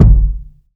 KICK.55.NEPT.wav